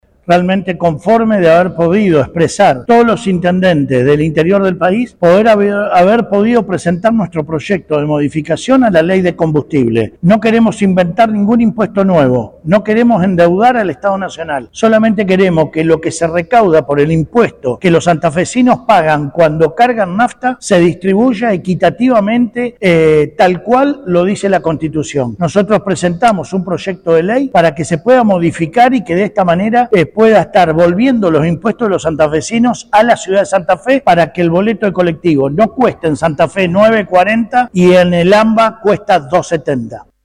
Al terminar la ronda de reuniones, Poletti declaró que salieron «conformes» de haber podido expresar la necesidad de los santafesinos y remarcó que presentaron un proyecto para modificar el impuesto a los combustibles para que la redistribución sea con mayor beneficio para el interior del país.
POLETTI-TRAS-REUNION-EN-SENADO-X-TRANSPORTE-Y-COMBUSTIBLE.mp3